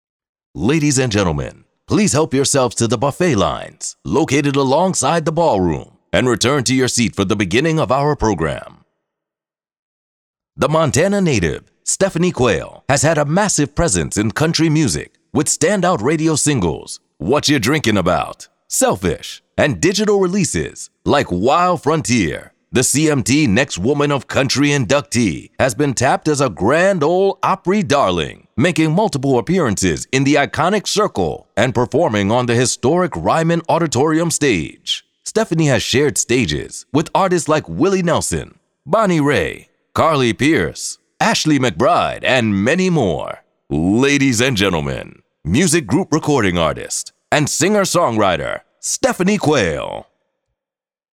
Profound. Resonant. Real | Voiceovers
Live Announcing
In particular, if you’re looking for an African American male voice, an urban sound, or even a neutral sounding tone, then look no further.